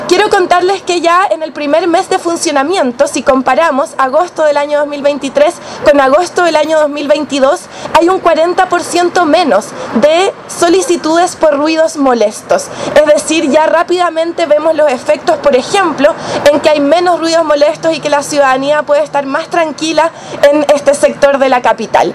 La jefa comunal señaló que desde el inicio de las obras de recuperación del parque en agosto del año 2022, los vecinos han percibido cambios en la convivencia, como por ejemplo, con los ruidos molestos originados desde su interior.
alcaldesa-iraci-hassler.mp3